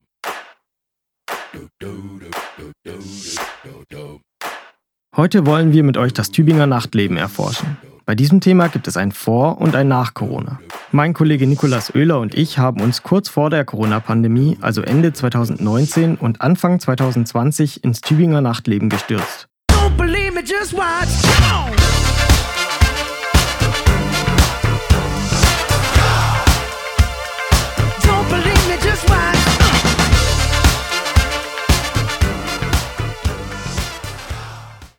477_Teaser.mp3